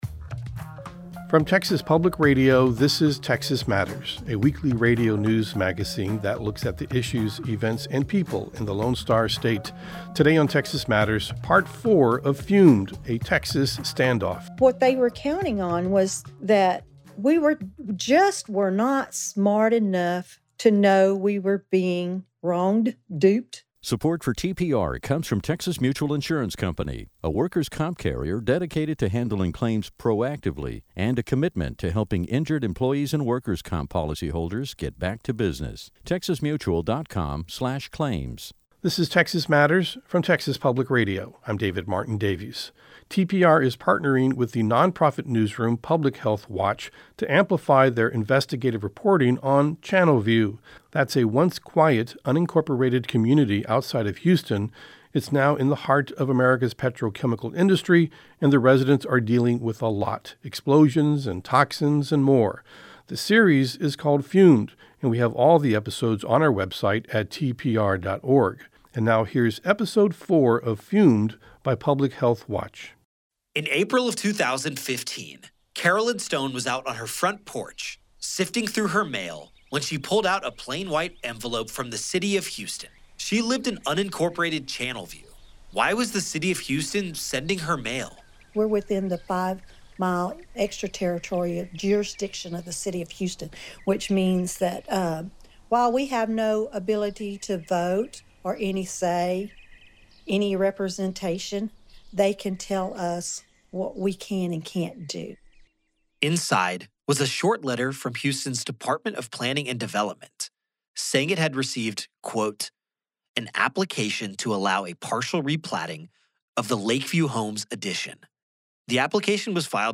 Texas Matters is a statewide news program that spends half an hour each week looking at the issues and culture of Texas.